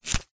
Звук отрывания ягоды от ветки